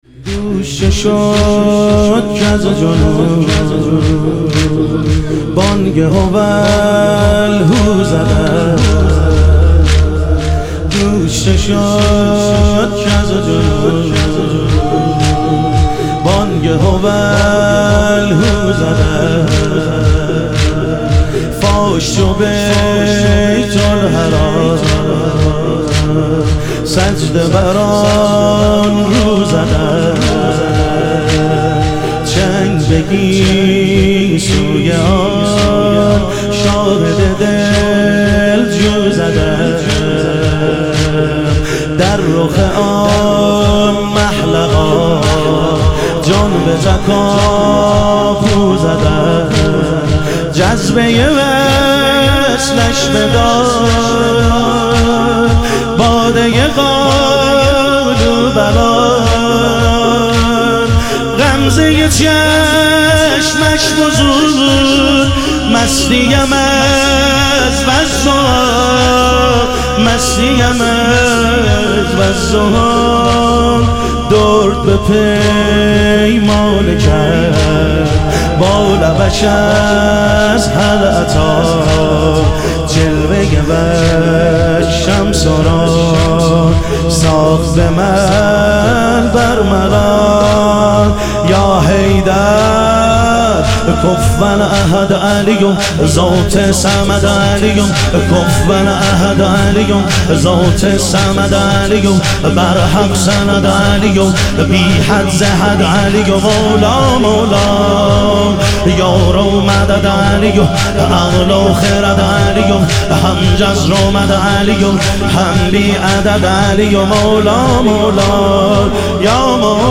لیالی قدر و شهادت امیرالمومنین علیه السلام - تک